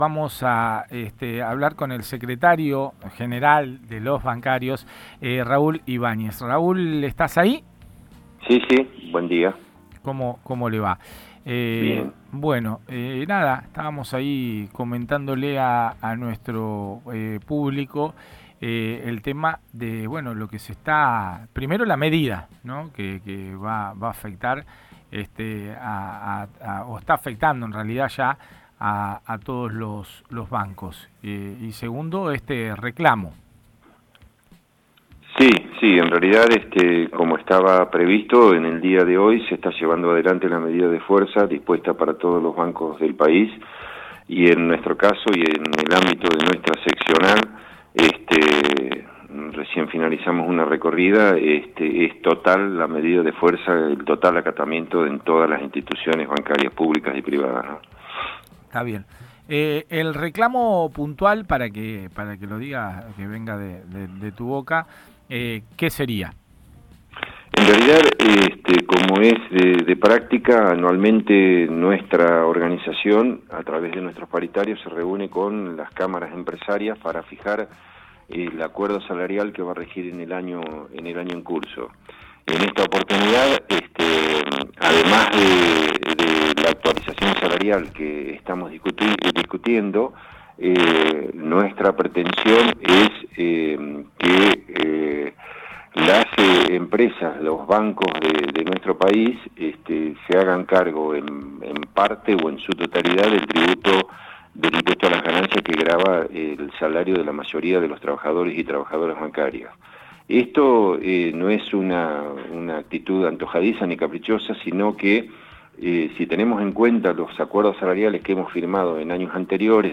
En comunicacion telefonica